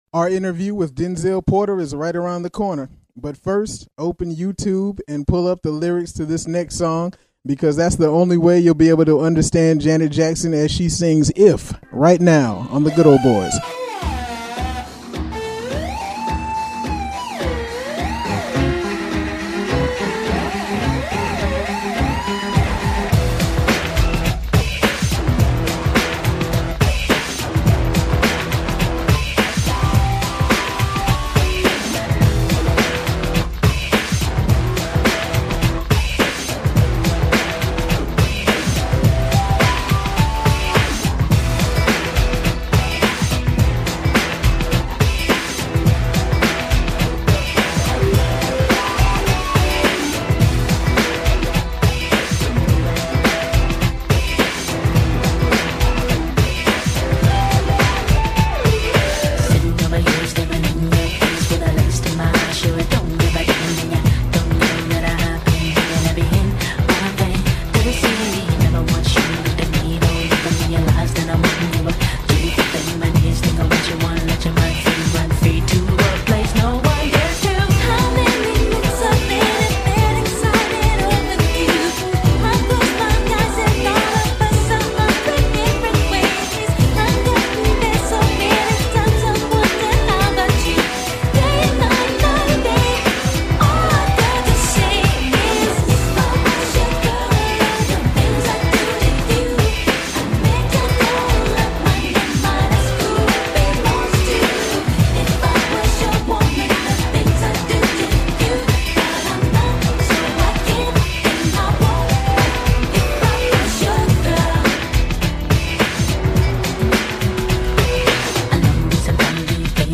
The Good Ole Boys brings fun mixed with great music and caps it with discussions that men between the ages of 25 - 55 are likely to have.